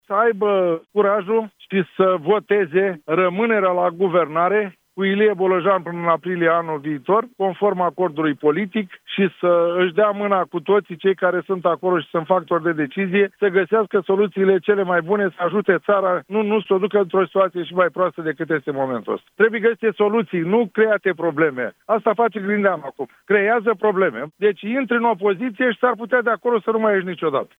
Luni, liderii social-democrați ar trebui să aibă curajul să voteze pentru rămânerea la guvernare cu Ilie Bolojan premier, conform acordului de guvernare, mai spune la Europa FM,  Constantin Toma.